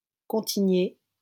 Contigné (French pronunciation: [kɔ̃tiɲe]